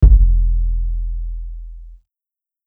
808s
KICK_NO_FURY_SUB.wav